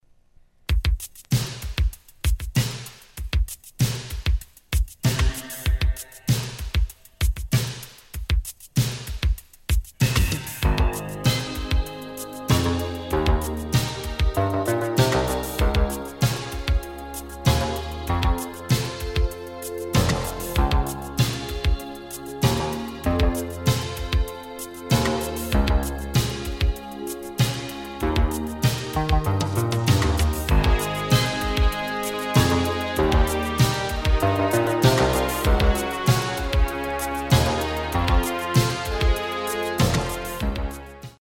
Record cleaned on the Project RC-S Record cleaning machine